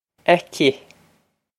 Pronunciation for how to say
ak-ee
This is an approximate phonetic pronunciation of the phrase.